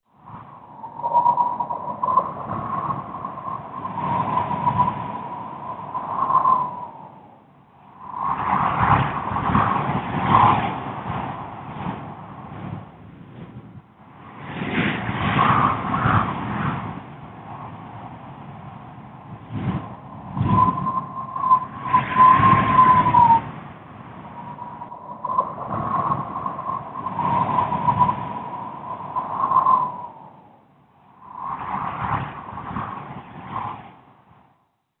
صوت عاصفة الصحراء -تغاريد البادية
الالات واصوات